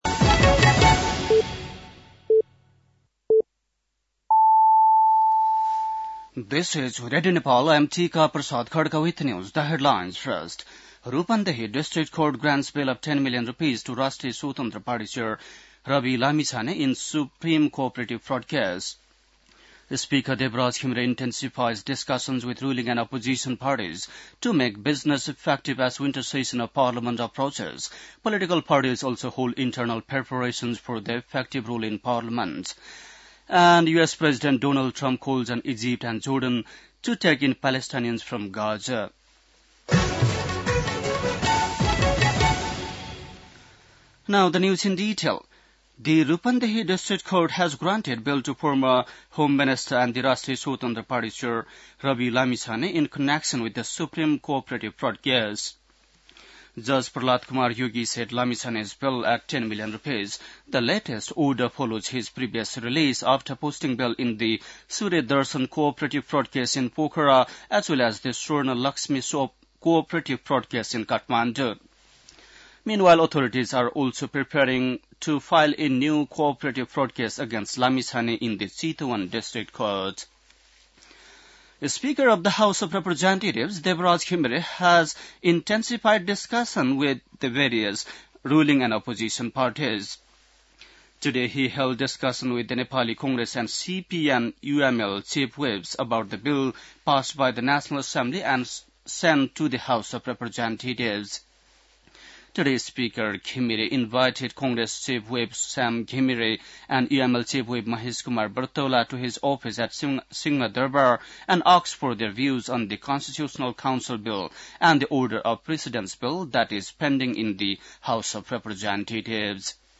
बेलुकी ८ बजेको अङ्ग्रेजी समाचार : १४ माघ , २०८१
8-pm-english-news-10-13.mp3